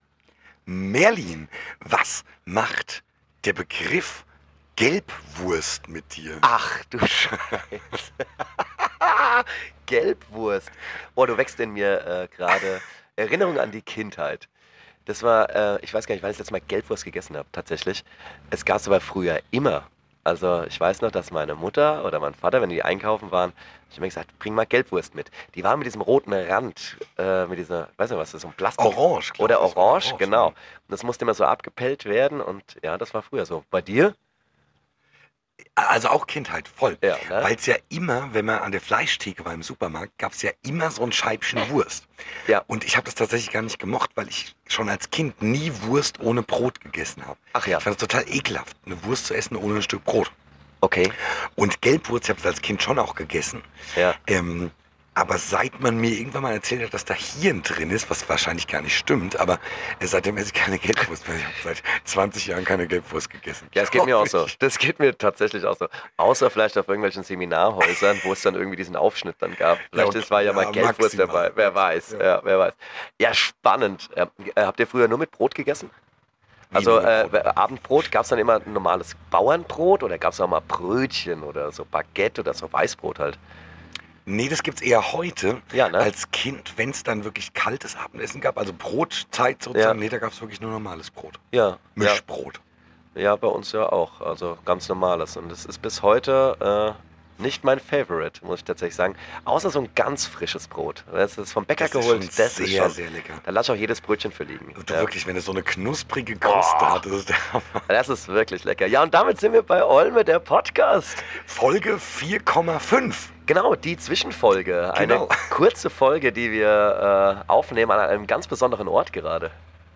Vor dem Livestream haben wir uns wieder zusammengesetzt und eine kleine Folge aufgenommen.
Wir hatten Spaß im grünen und wie man hört direkt am Bahnhof.